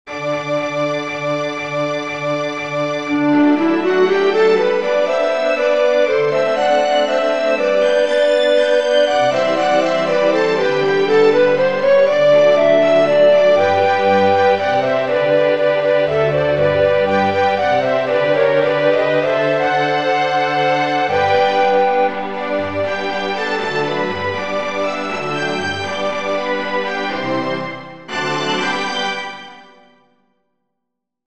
Reprise du choeur